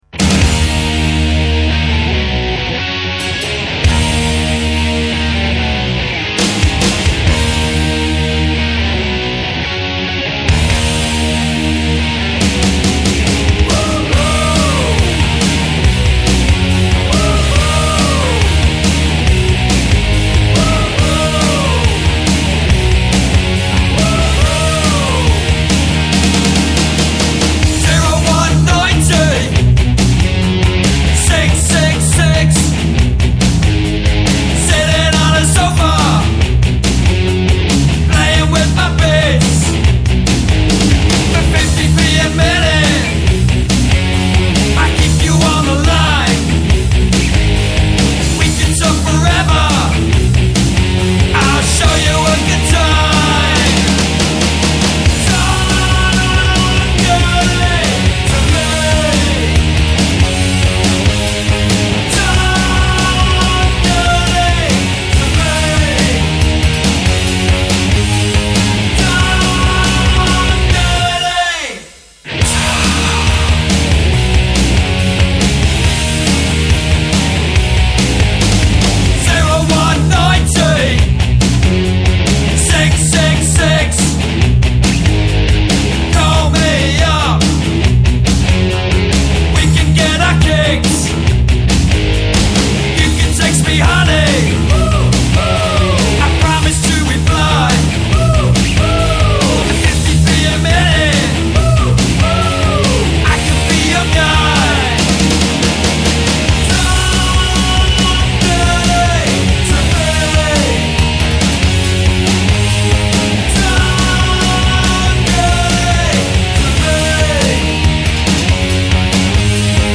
Drums, Percussion, Swooshy Noises and Backing Vox
Bass and Occasional Grunts